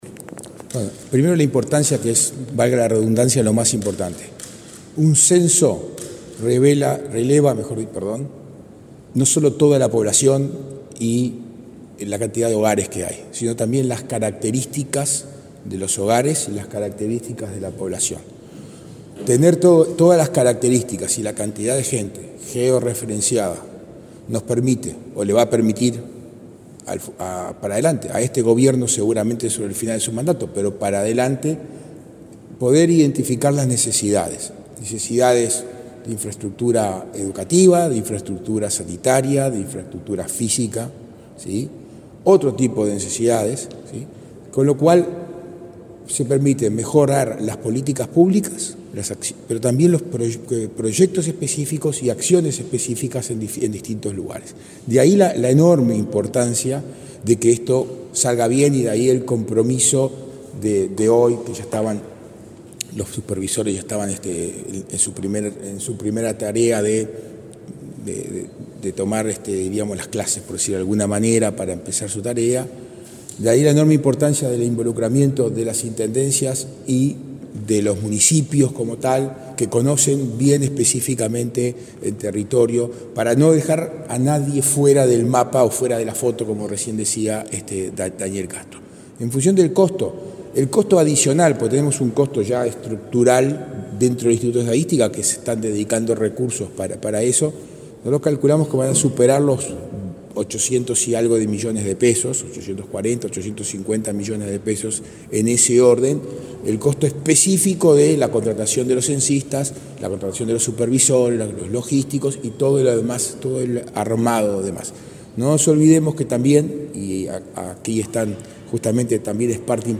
Declaraciones del director de OPP y el presidente del Congreso de Intendentes
Este martes 29, el director de la Oficina de Planeamiento y Presupuesto (OPP), Isaac Alfie, y el presidente del Congreso de Intendentes, Guillermo López, participaron en la presentación de la campaña de reclutamiento de censistas que trabajarán en el próximo Censo de Población, Hogares y Viviendas 2023. Luego dialogaron con la prensa.